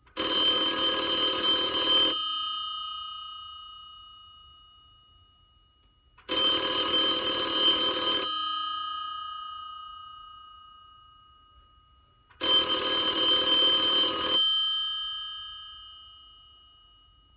ringtone.alaw.wav